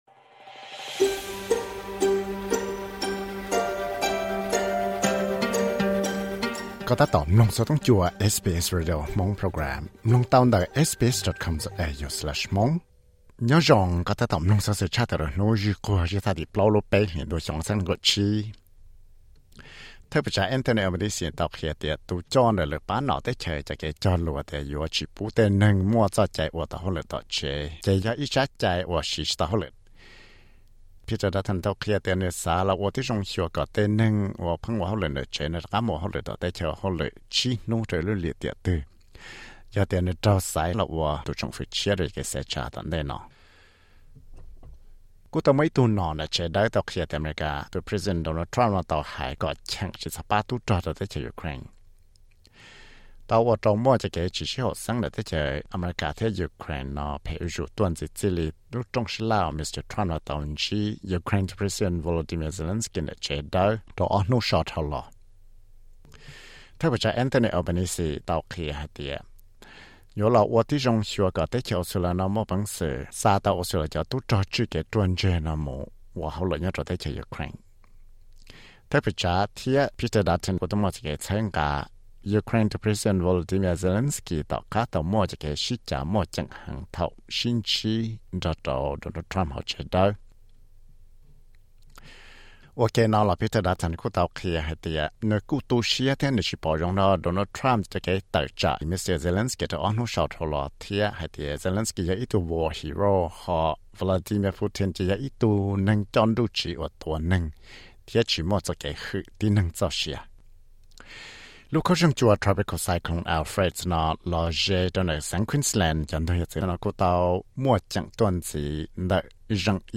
Xov xwm luv